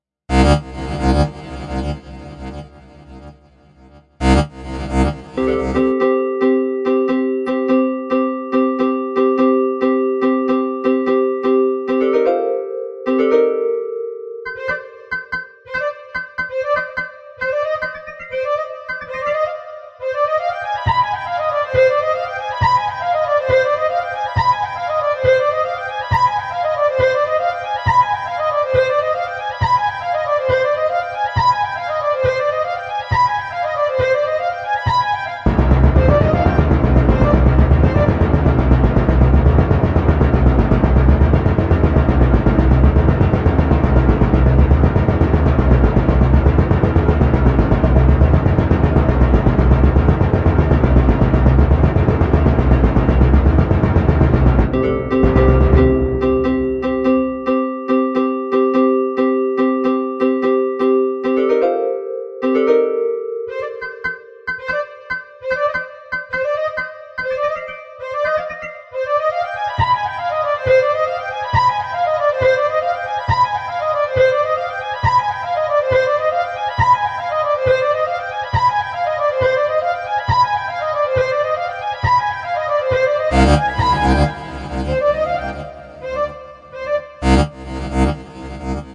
管弦乐队 " 吉他 小提琴 鼓
描述：由FL工作室创作的吉他小提琴声音
Tag: 字符串 小提琴 声学 吉他 电影 乐队 管弦乐 背景